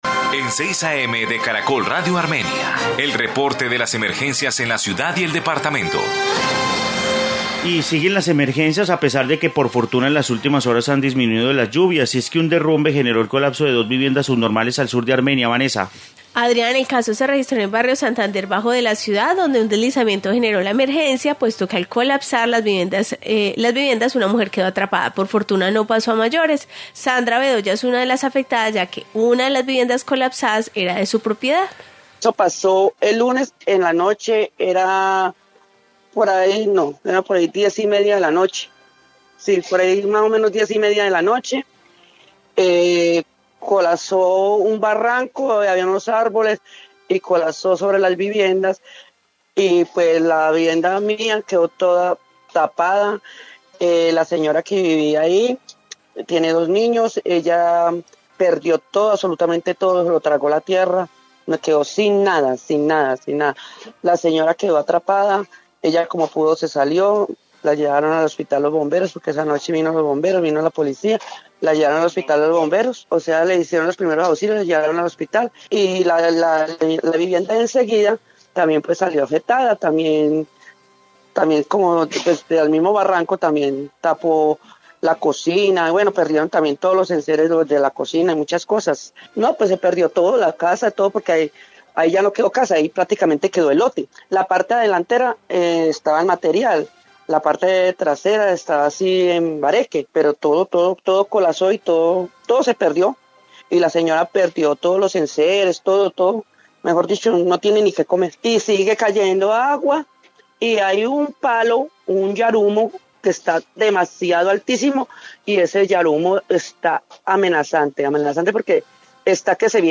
Informe sobre derrumbe en Armenia